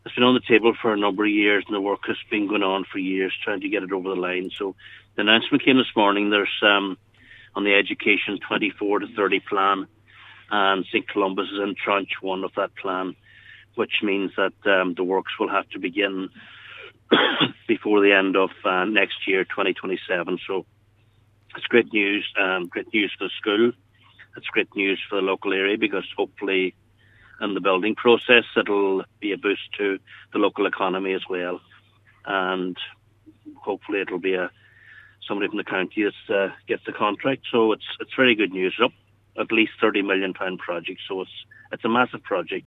Meanwhile, Cathaoirleach of the Lifford–Stranorlar Municipal District, Councillor Martin Harley, says the St. Columba’s project has been a long time coming: